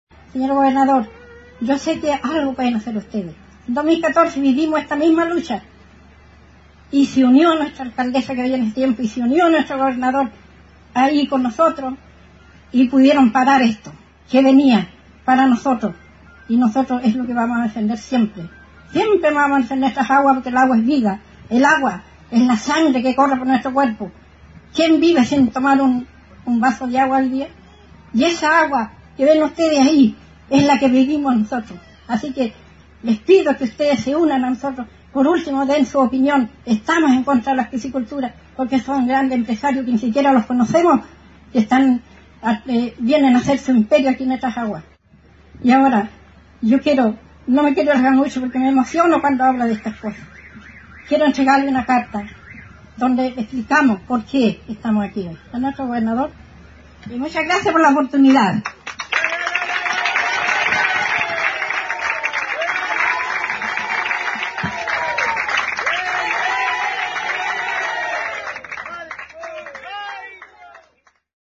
Durante la ceremonia del día mundial de los humedales actividad desarrollada en el camping municipal ubicado en el Puerto Viejo de Trumao, comuna de La Unión.